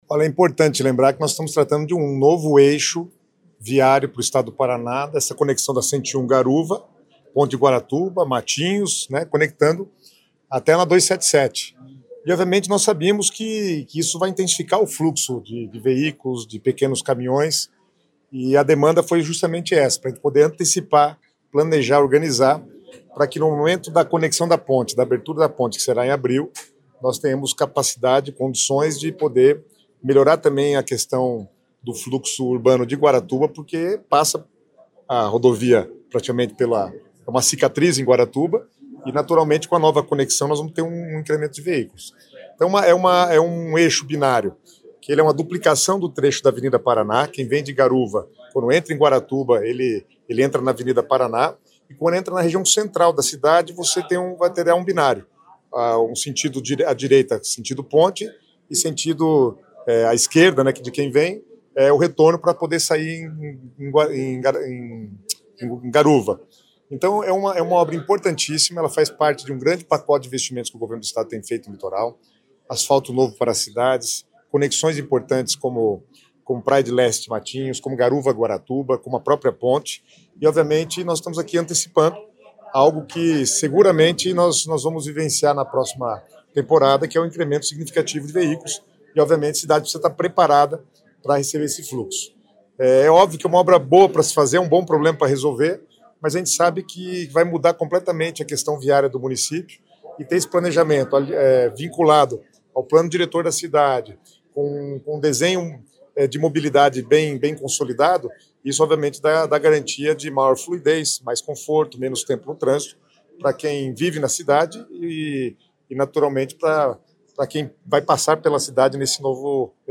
Sonora do secretário Estadual das Cidades, Guto Silva, sobre o novo binário de Guaratuba